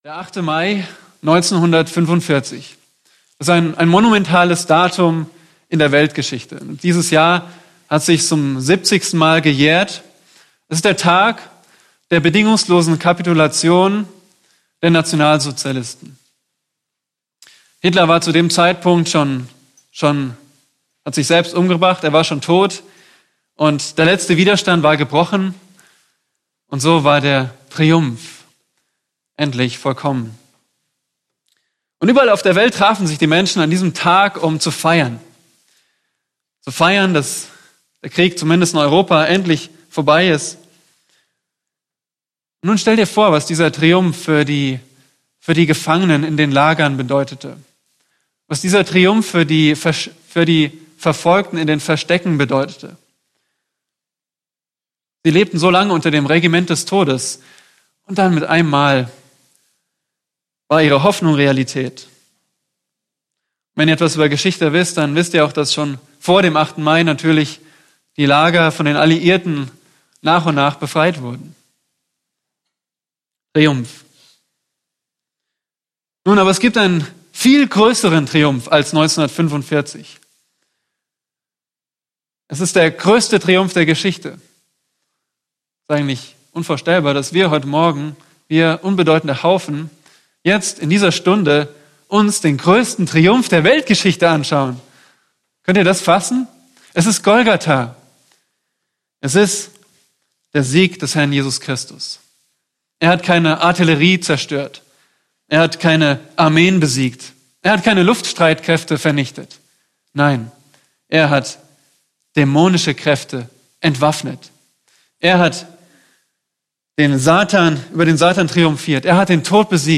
Eine predigt aus der serie "Lieder von Herzen."